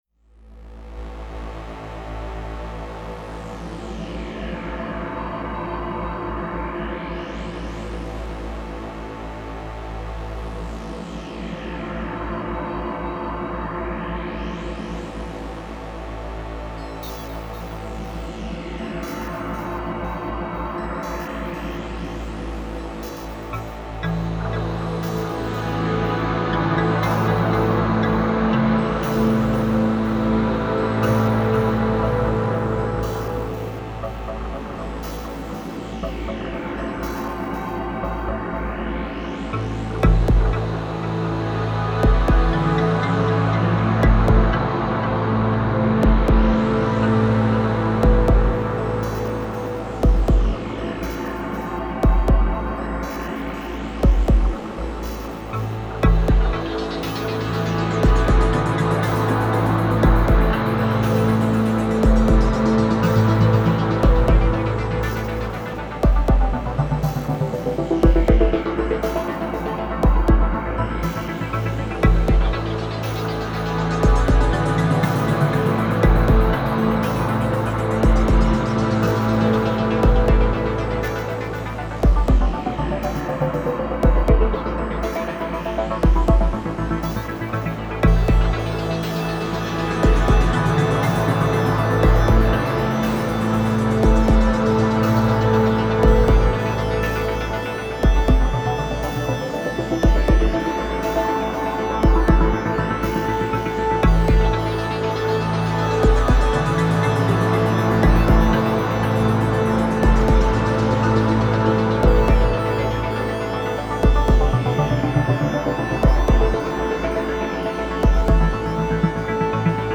This short piece was created for a dystopian video game that was unfortunately never completed.